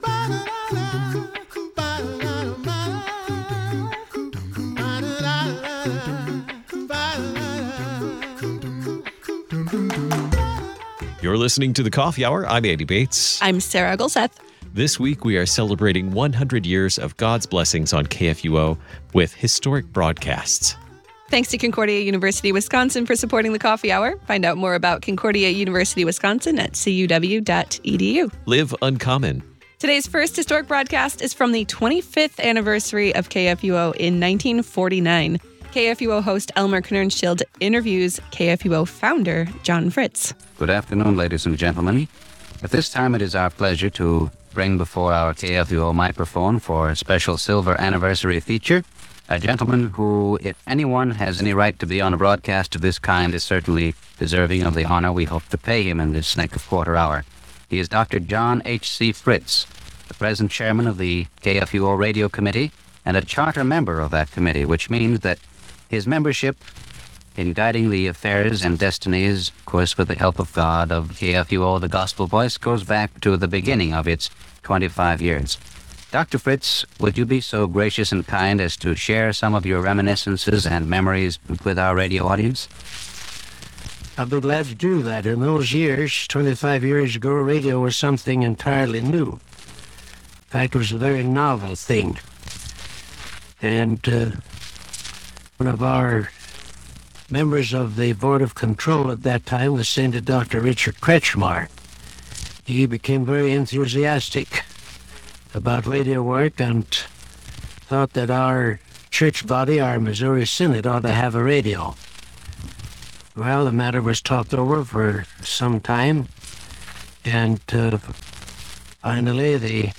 We celebrate 100 years of God’s blessings on KFUO with special historic broadcasts.
These broadcasts are from the 25th Anniversary of KFUO in 1949.